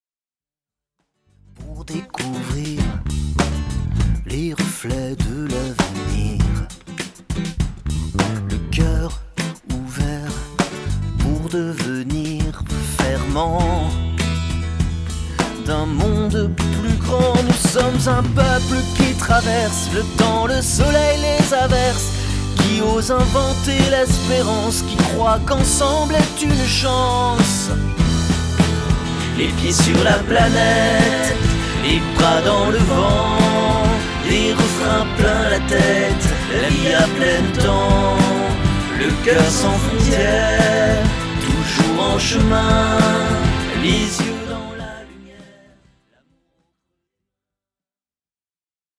ses percussions corporelles